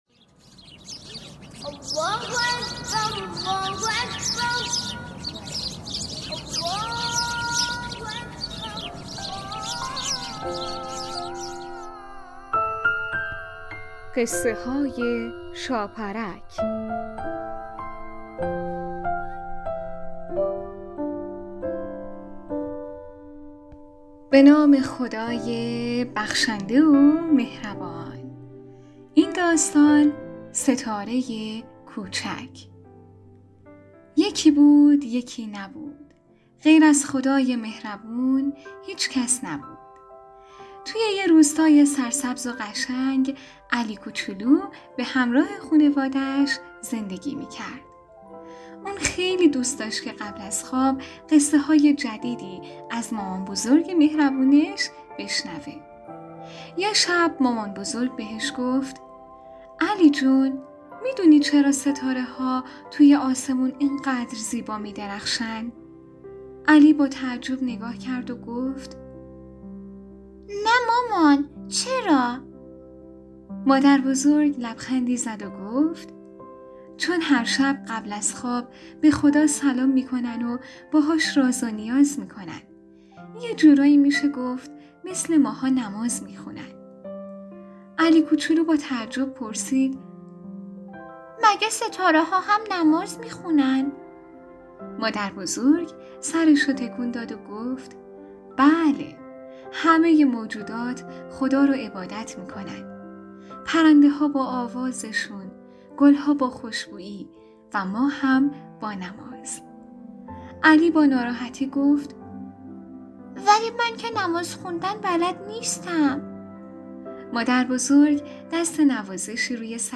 قسمت صد و بیست و هشتم برنامه رادیویی قصه های شاپرک با نام ستاره کوچک یک داستان کودکانه مذهبی با موضوعیت نماز